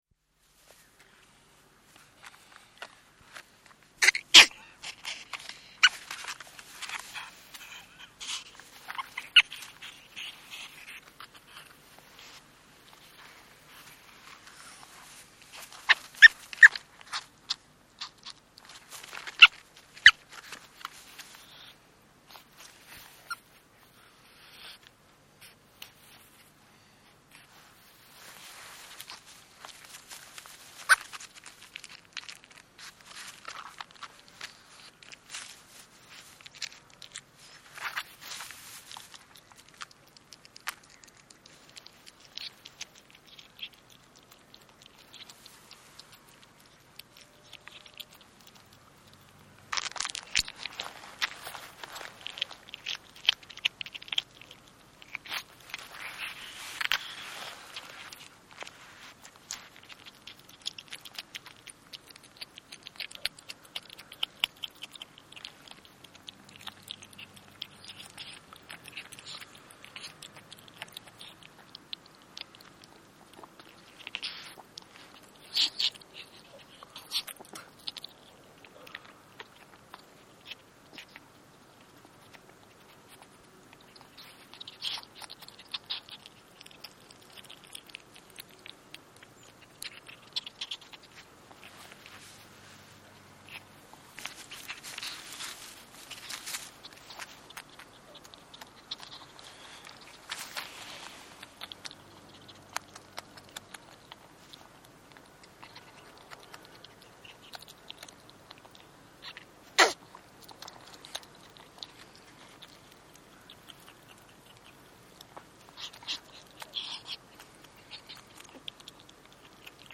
На этой странице собраны разнообразные звуки хорьков: от игривого попискивания до довольного урчания.
Звуки лесного хорька в естественной среде